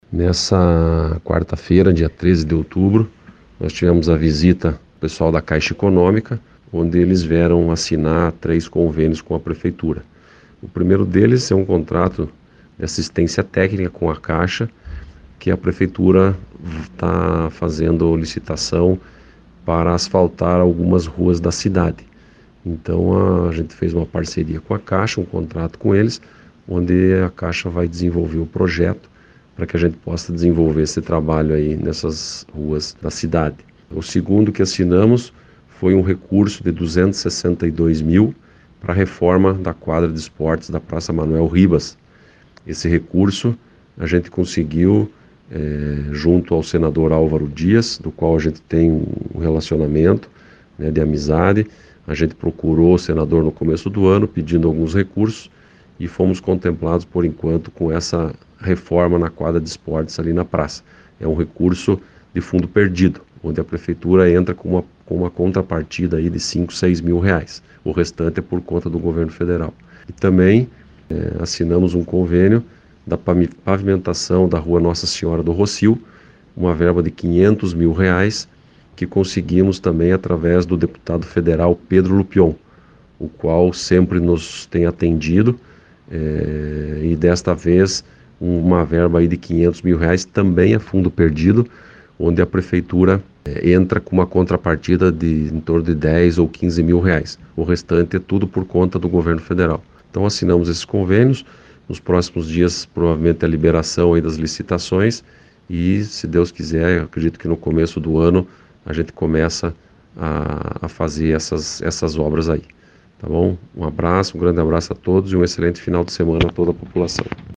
O Prefeito Sergio Belich comentou sobre as conquistas para Palmeira.